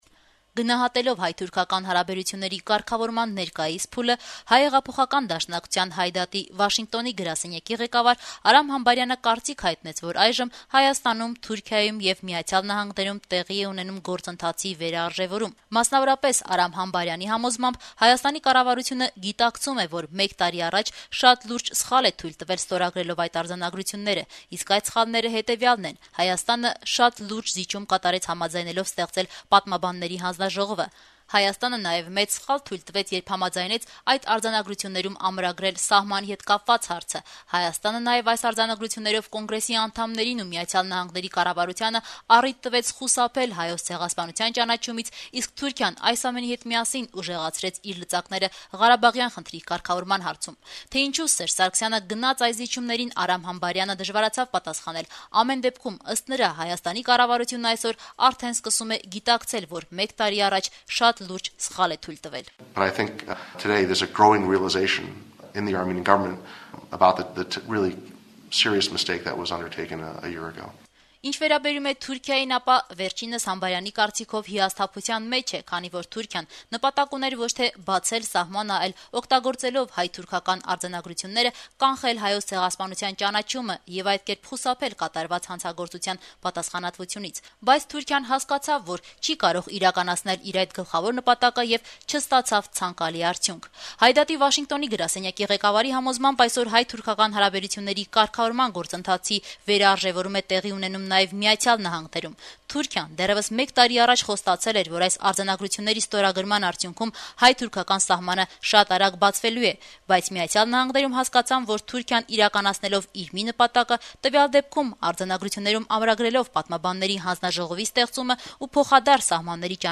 «Ազատություն» ռադիոկայանի հետ զրույցում